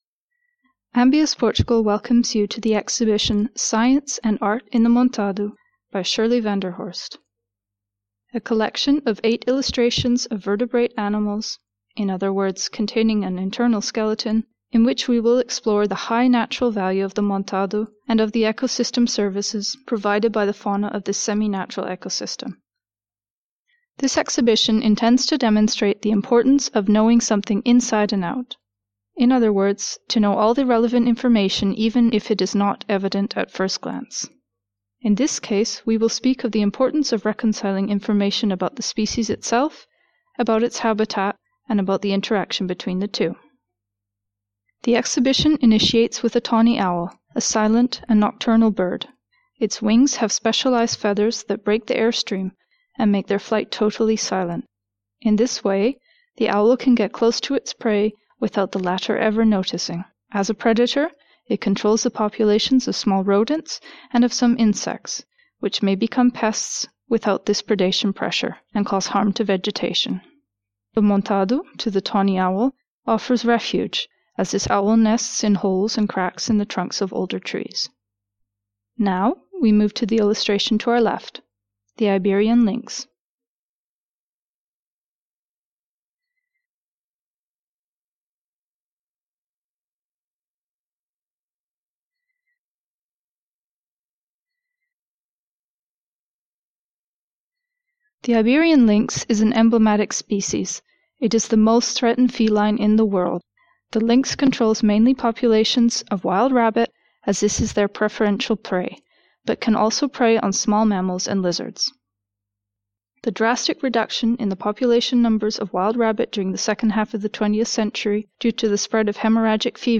Audio guide for the exhibition in english